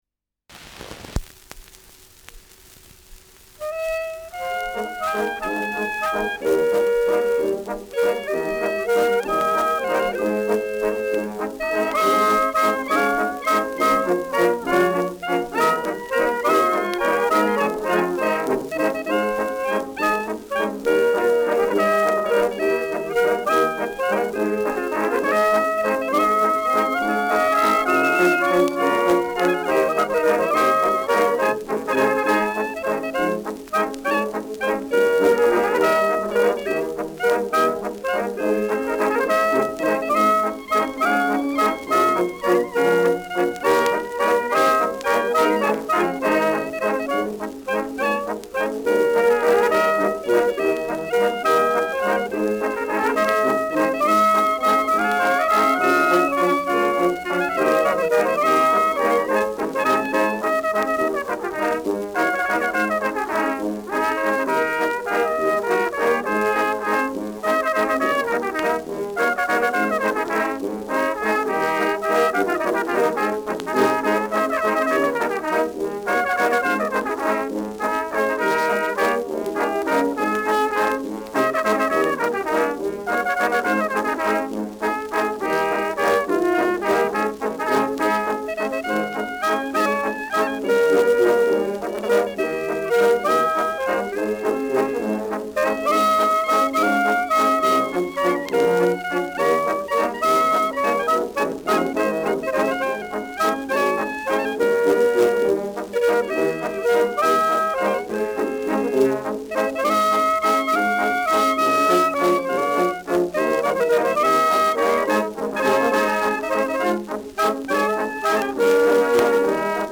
Schellackplatte
Tonrille: Kratzer 10 Uhr Stark
Mit Juchzern.